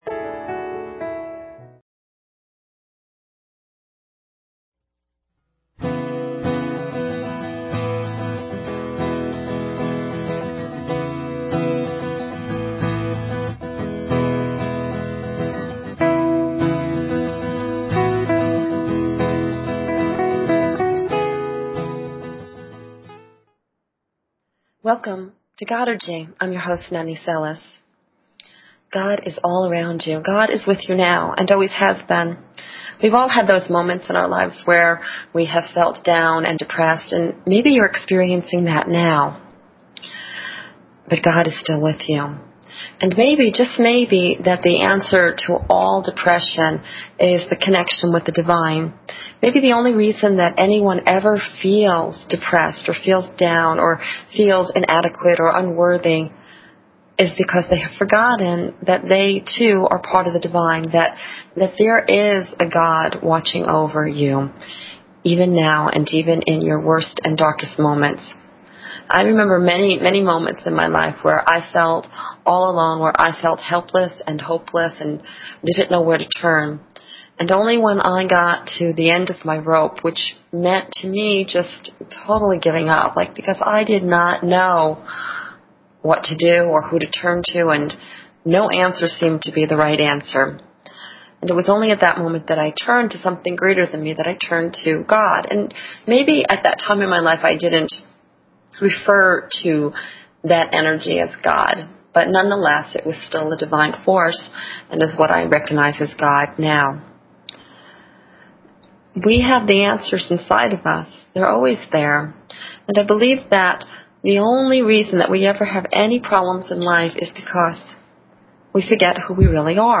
Talk Show Episode, Audio Podcast, Godergy and Courtesy of BBS Radio on , show guests , about , categorized as